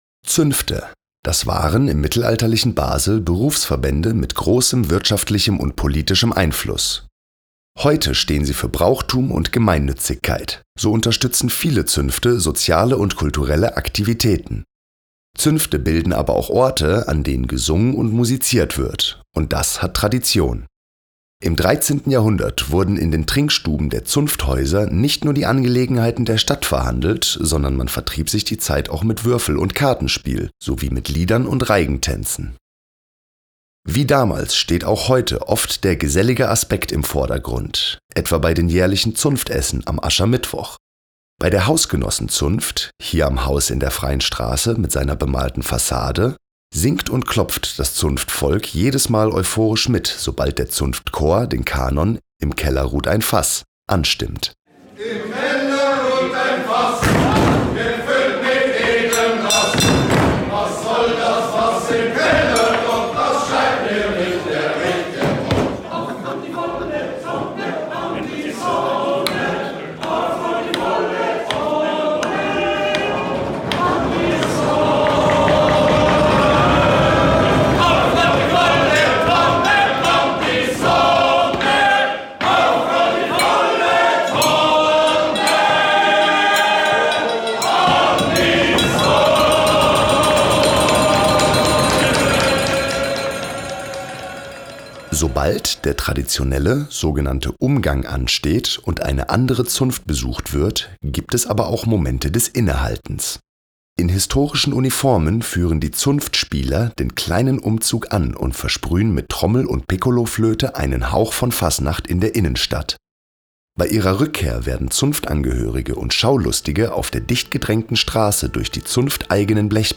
QUELLEN der Musikbeispiele:
(Trompete).